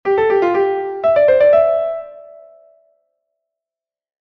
Grupeto de 4 notas
O grupeto será de catro notas cando o signo vaia entre dúas notas diferentes ou cando vai precedido por un mordente.